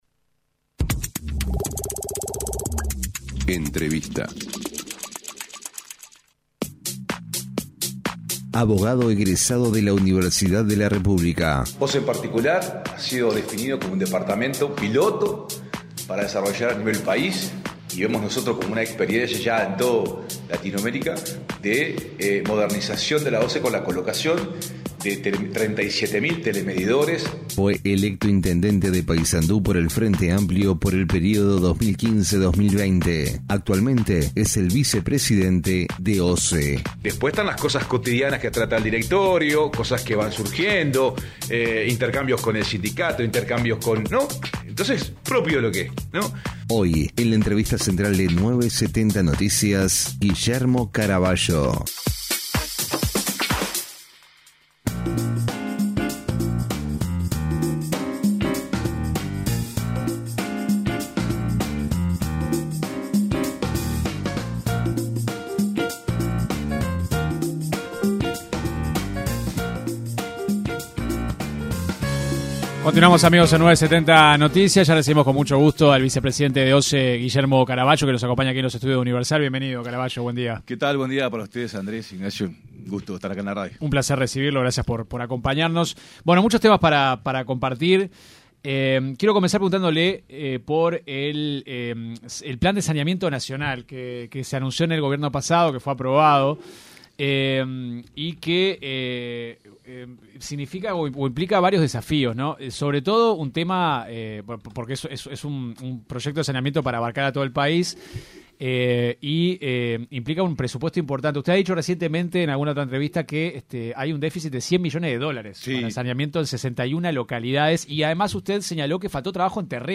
El vicepresidente de OSE, Guillermo Caraballo criticó en diálogo con 970 Noticias, la financiación del proyecto de saneamiento que impulsó el Gobierno anterior de Luis Lacalle Pou.